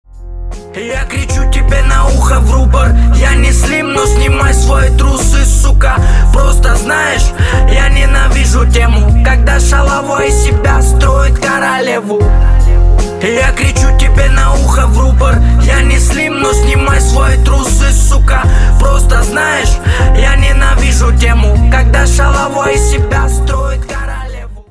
• Качество: 320, Stereo
мужской вокал
русский рэп